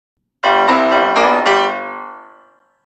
Ту-ду-ду-ду-ту